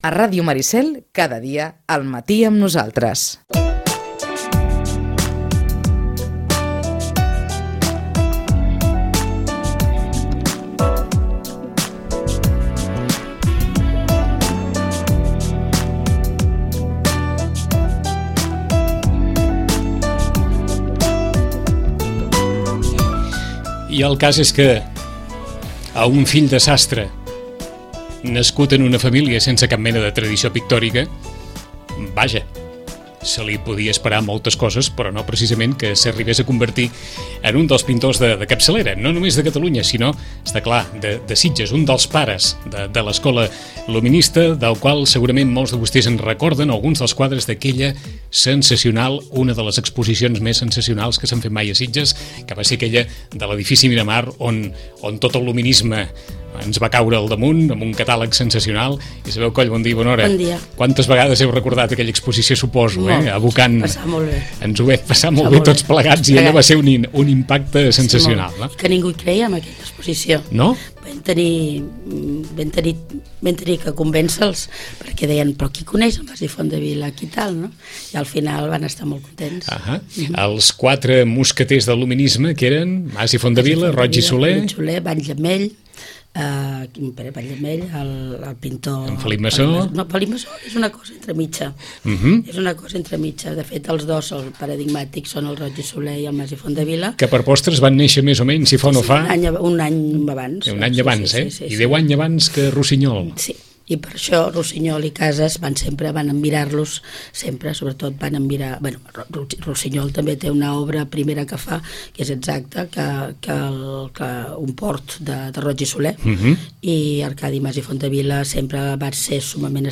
Conversem amb ella sobre la vida i l’obra d’un dels pintors de capçalera de l’art català contemporani.